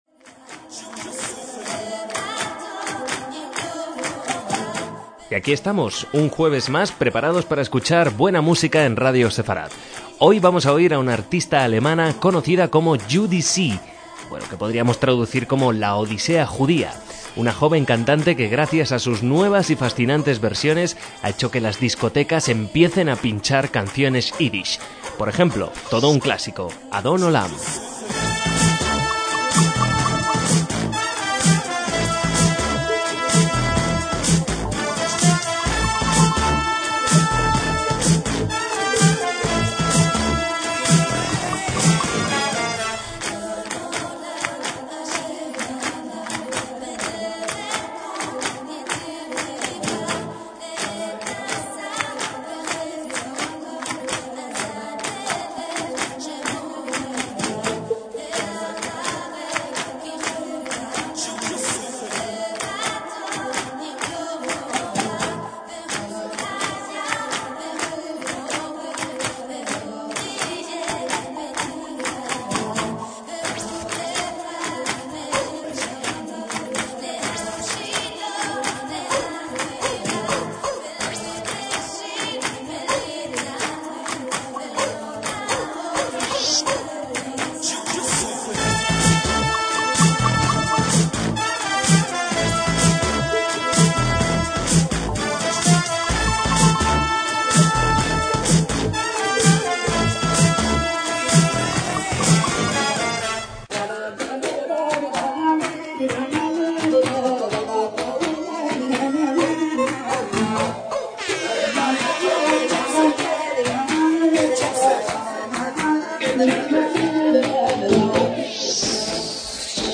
Si sonido original es un cruce de la llamada cultura de la balalaika con la música electrónica de clubes, matizadas con aromas del Mediterráneo y el Oriente Próximo